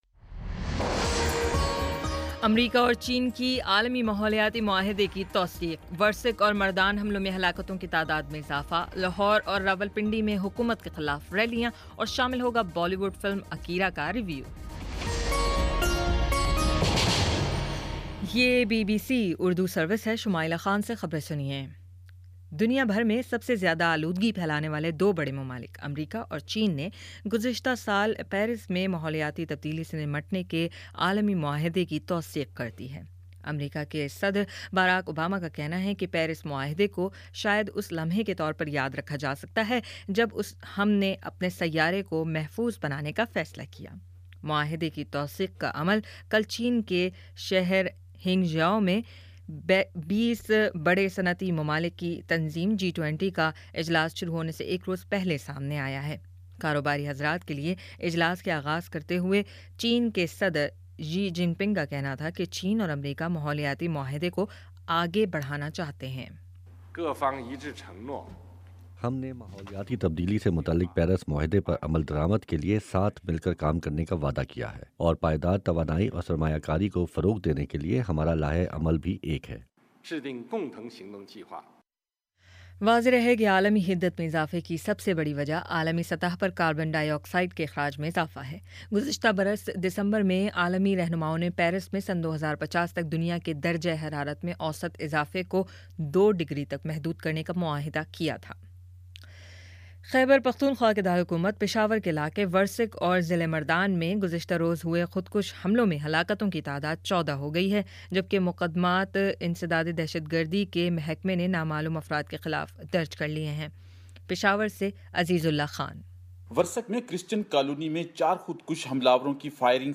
ستمبر03 : شام چھ بجے کا نیوز بُلیٹن